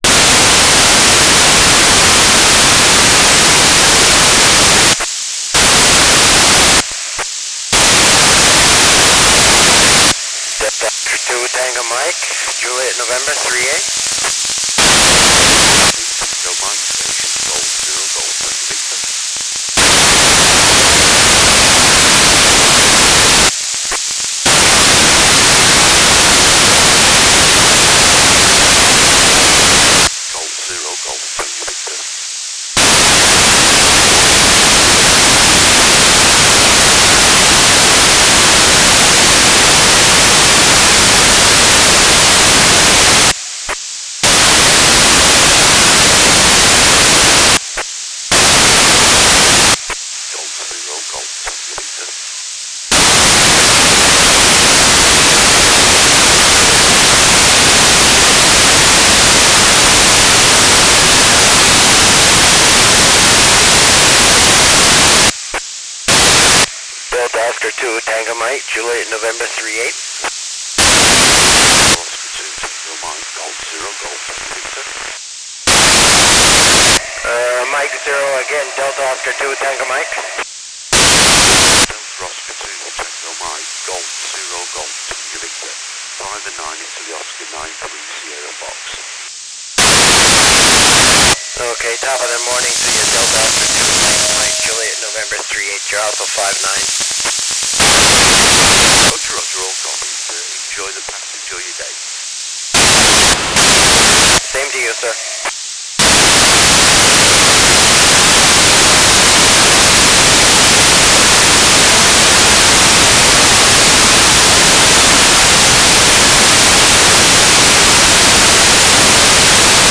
"transmitter_description": "Mode V/U FM - Voice Repeater CTCSS 67.0 Hz",
"transmitter_mode": "FM",